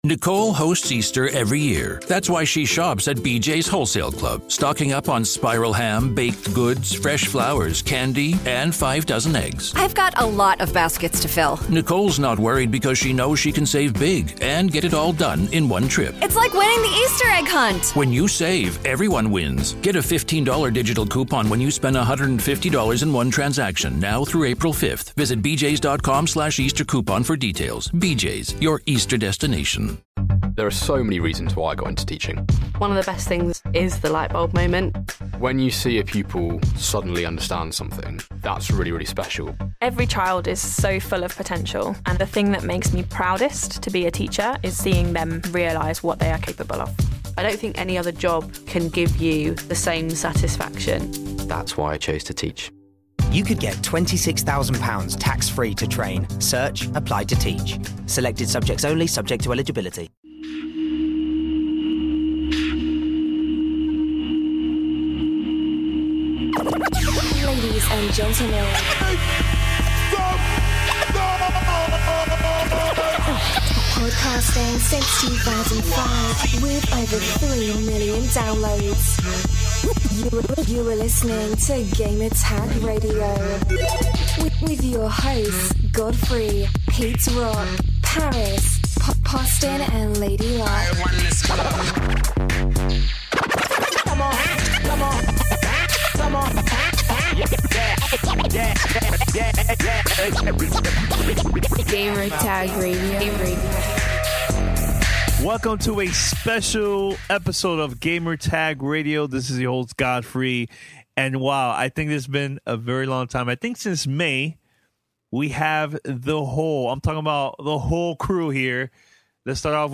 This week on Gamertag Radio: The crew gets together for a roundtable discussion about Destiny.